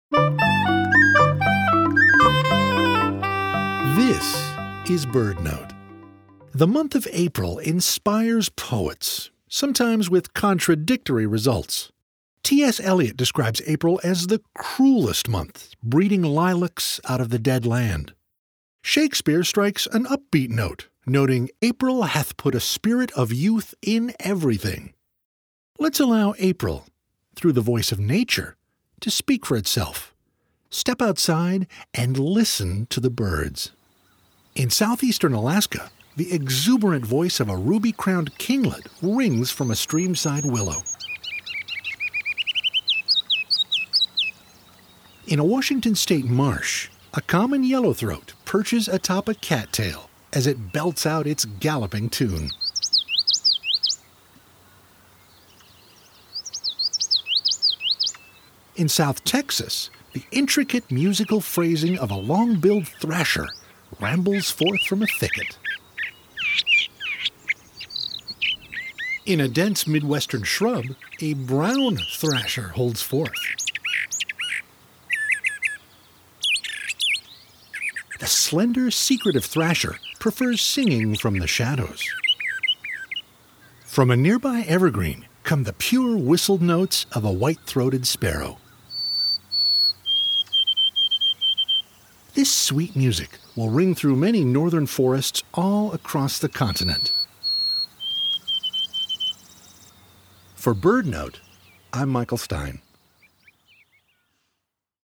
Bird Songs of April (BirdNote: 04/28/25) – Chirp Nature Center
Listen to the birds.